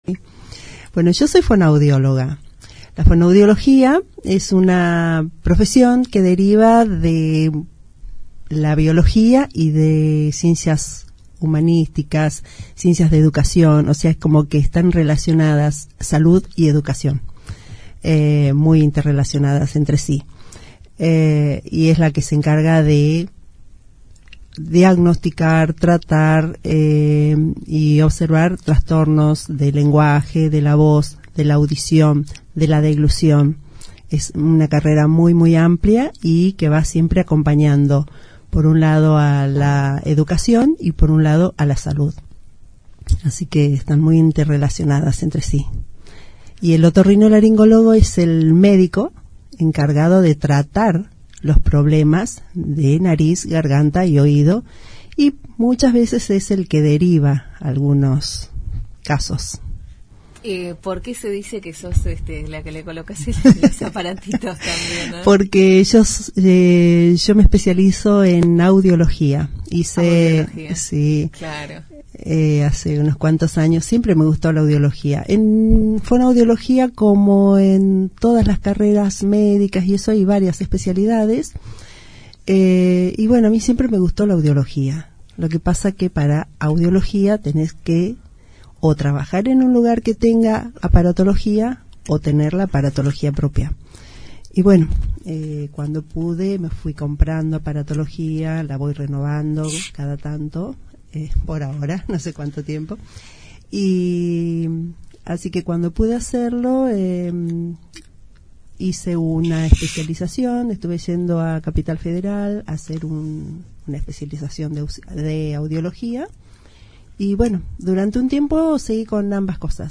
En una interesante charla con la 91.5 la reconocida fonoaudióloga se refirió a su profesión a la vez que brindó consejos para el cuidado de la voz y el oído.